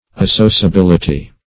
associability - definition of associability - synonyms, pronunciation, spelling from Free Dictionary
Associability \As*so`cia*bil"i*ty\, n.